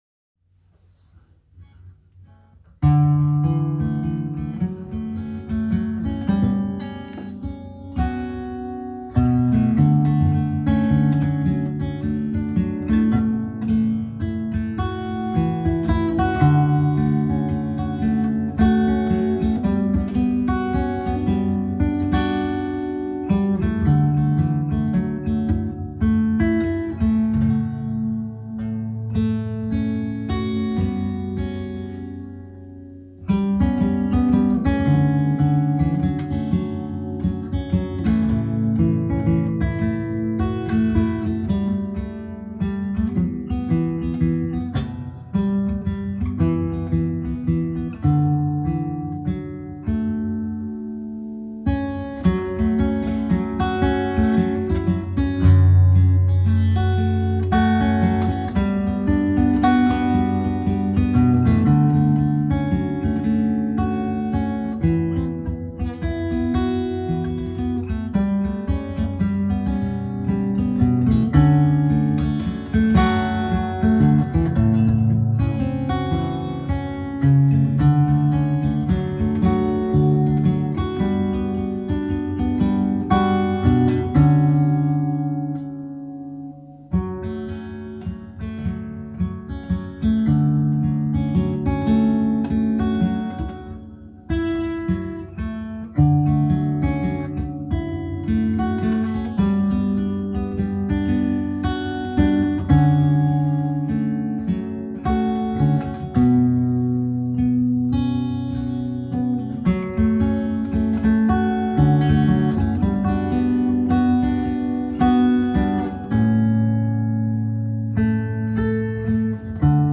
Tonbeispiel Gitarre
Impro pfingsten 2020.m4a